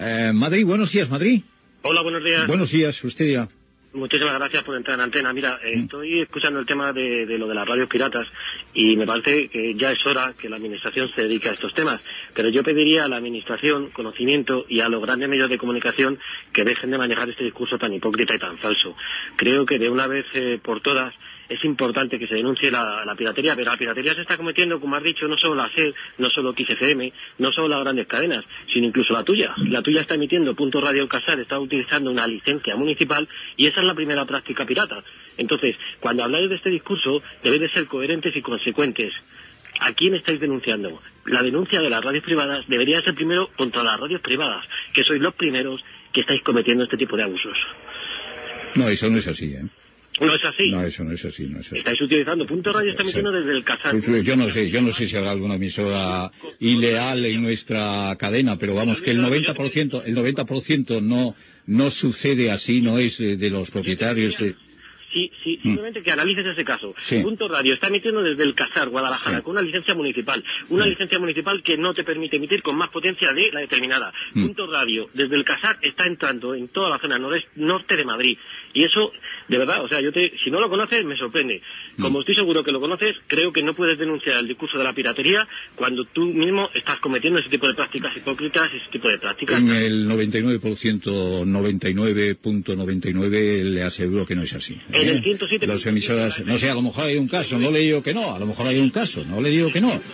Intervenció d'un oient sobre la pirateria radiofònica i discussió amb el presentador
Info-entreteniment